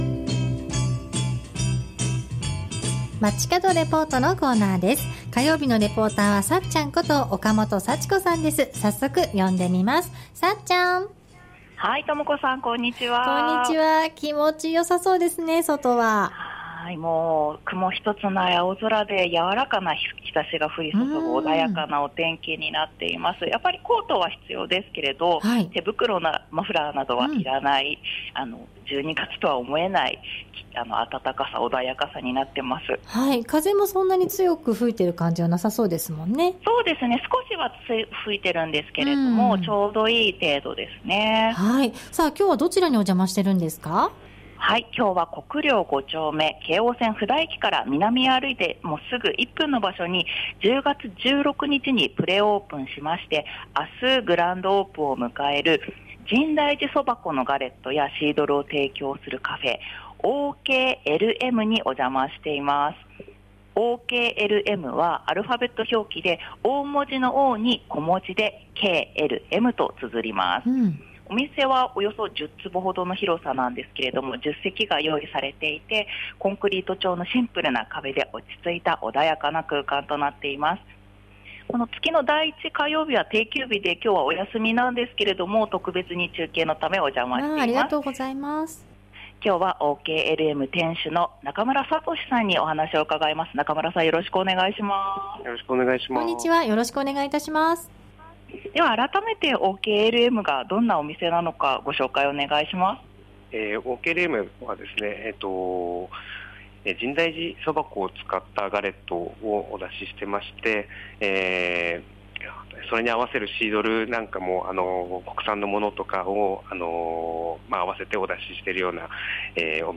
中継は京王線・布田駅から歩いてすぐの場所に10月16日にプレオープンし、明日（12月4(日)）にグランドオープンを迎える 深大寺そば粉のガレットやシードルを提供するお店「Oklm(オーケーエルエム)」からお届けしました。